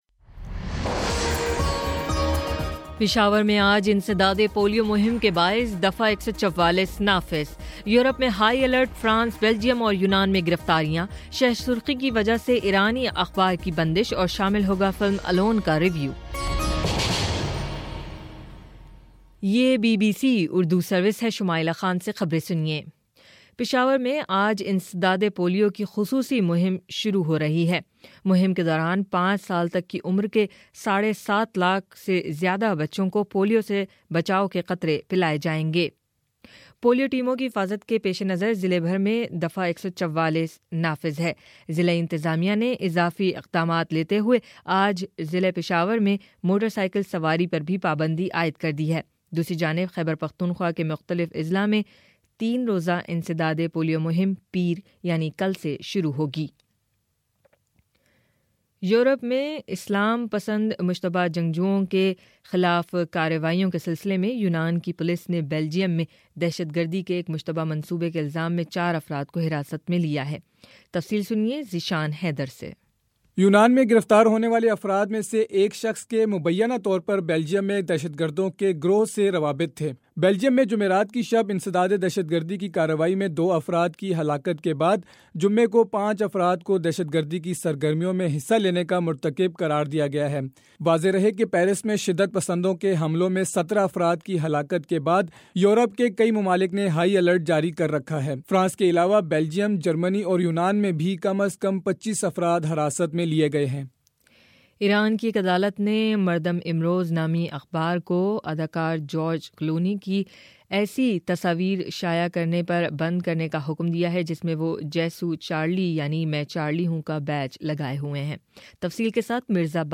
جنوری 18: صبح نو بجے کا نیوز بُلیٹن